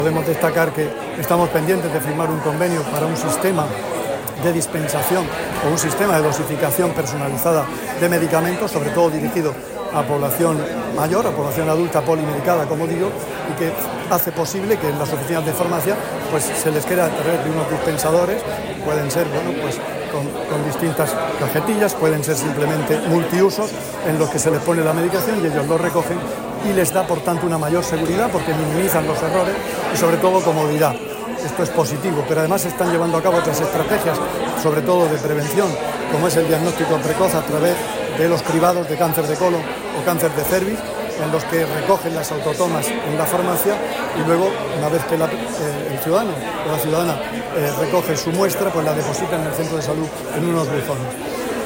Declaraciones del consejero de Salud, Juan José Pedreño, sobre el Día Mundial del Farmacéutico. [MP]
El consejero de Salud, Juan José Pedreño, participa en el acto institucional por el Día Mundial del Farmacéutico, celebrado en Santomera.